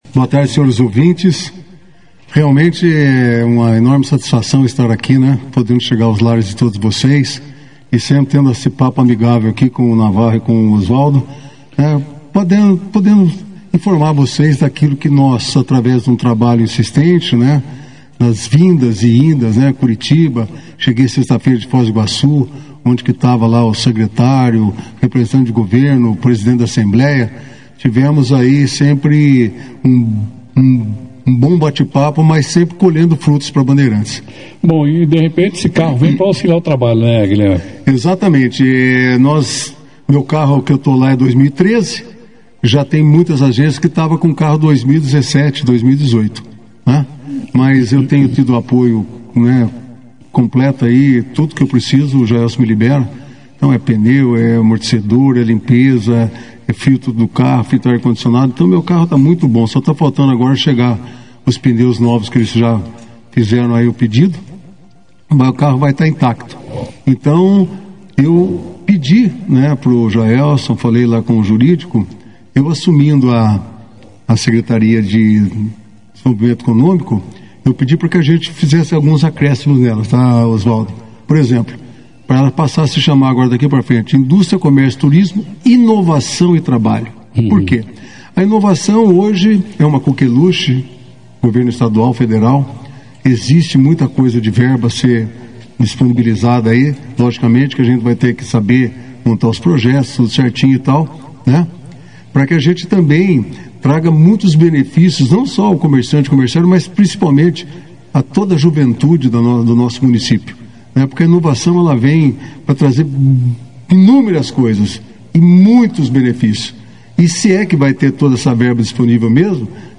O secretário de Desenvolvimento Econômico de Bandeirantes e coordenador do Sine, Guilherme Meneghel, (foto), participou nesta quarta-feira (12) da 2ª edição do Jornal Operação Cidade, destacando as mais recentes conquistas para a Agência do Trabalhador do município.